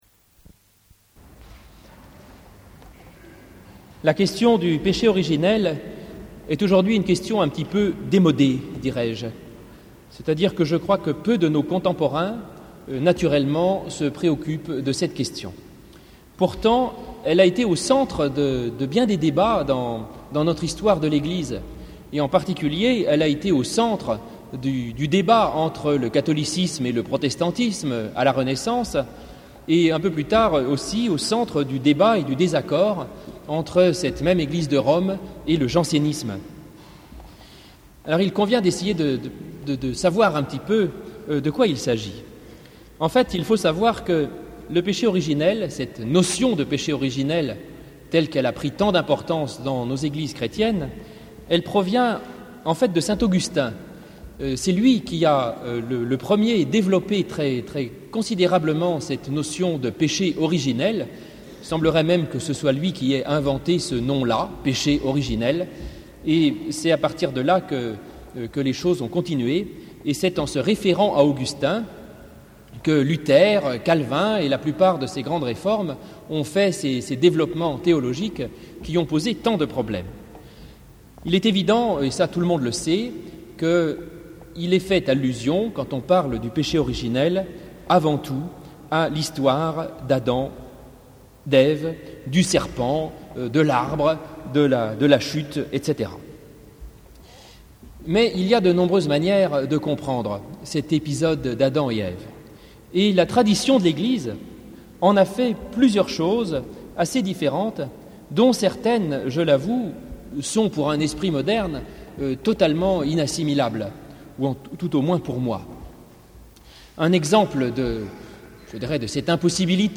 Cultes et prédications - Page #17